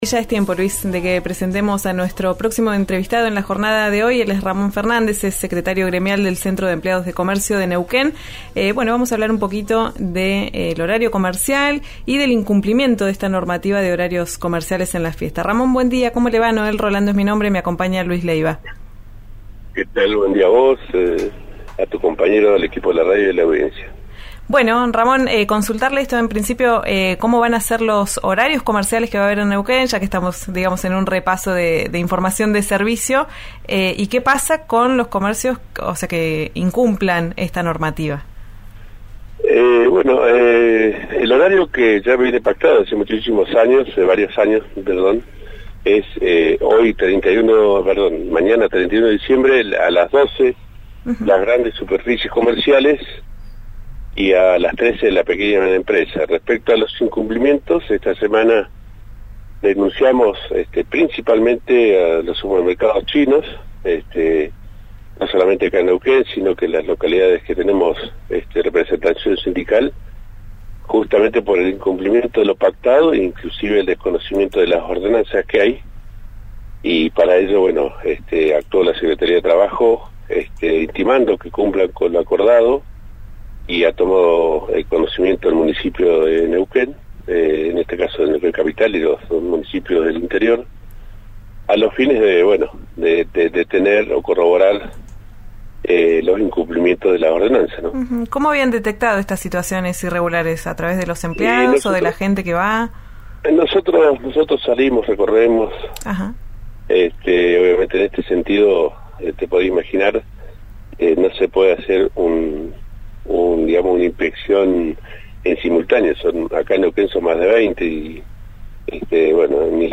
en Ya Es Tiempo, por RÍO NEGRO RADIO.